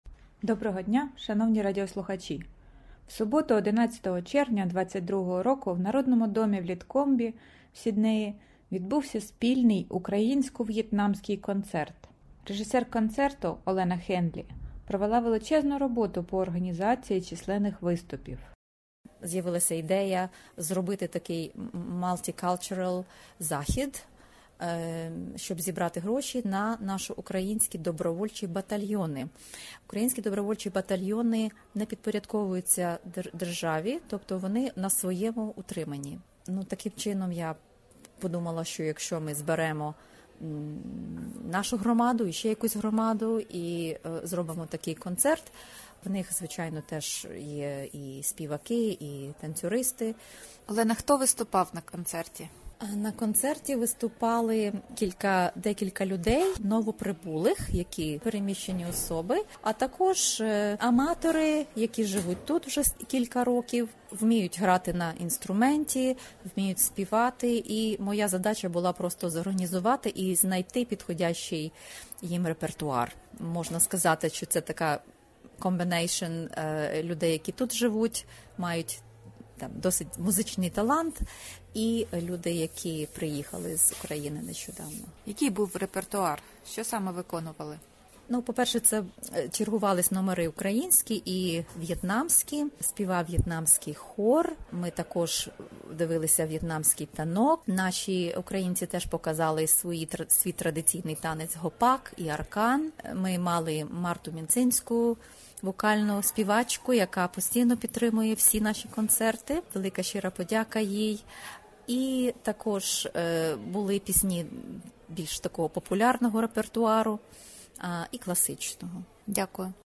радіосеґменті